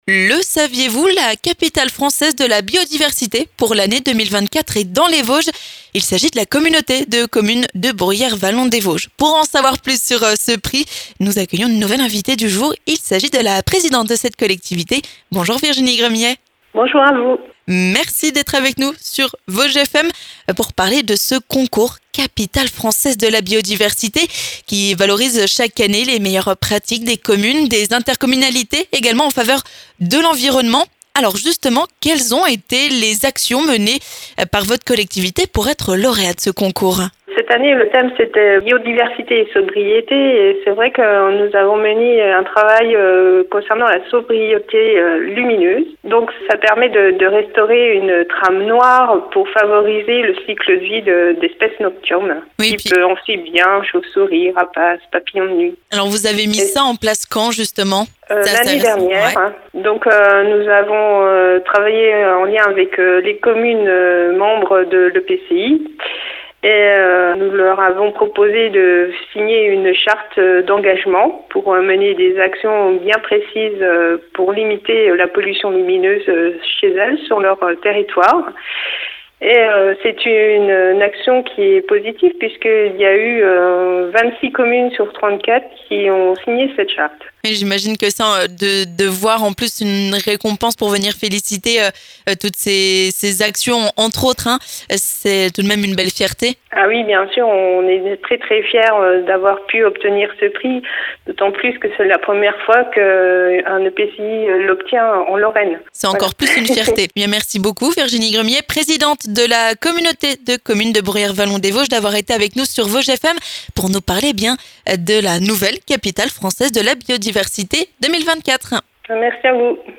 Notre invitée du jour, Virginie Gremillet, présidente de la collectivité, nous parle de ce concours qui récompense les collectivités qui œuvrent pour l’environnement.